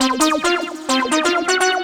FLUTISH ARZ 2.wav